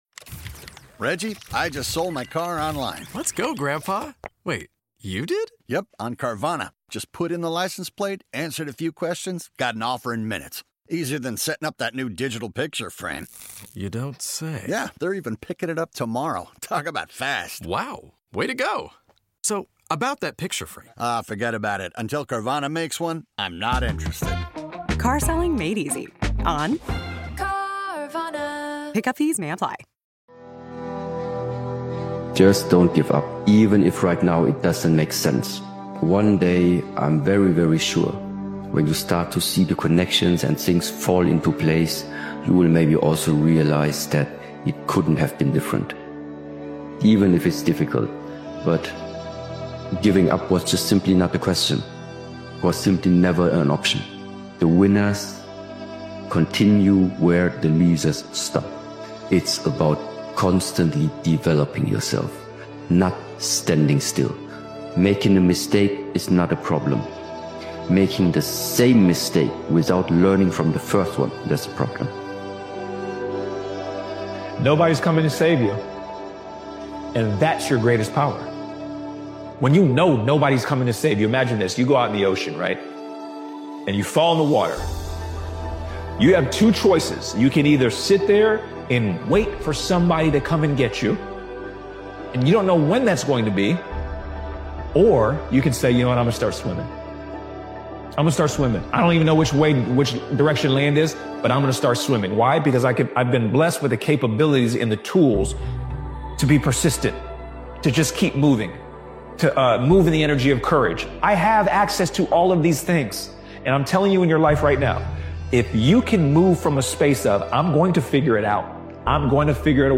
Powerful Motivational Speech is a relentless and emotionally charged motivational speech created and edited by Daily Motivation. This powerful motivational speeches compilation is built for the moments when quitting feels justified and walking away seems easier. It’s a reminder that endurance outlasts talent, and persistence beats circumstance.